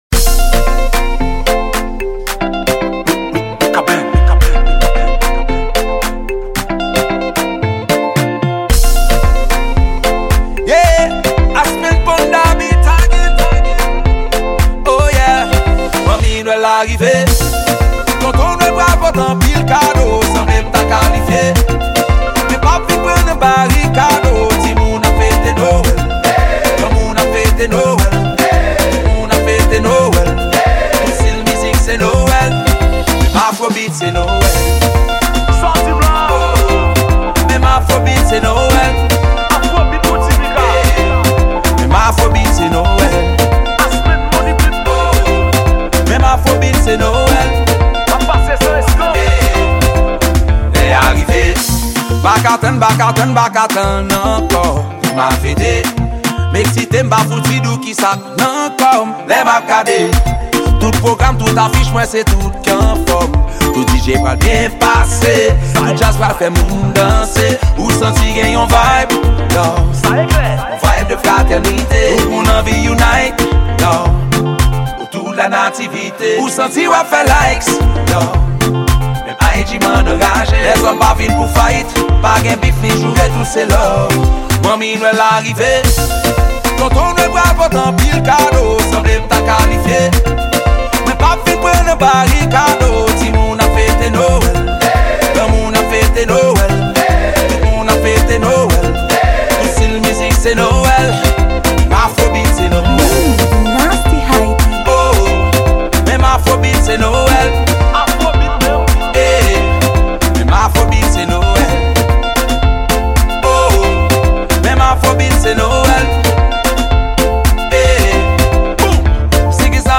Genre: Noel.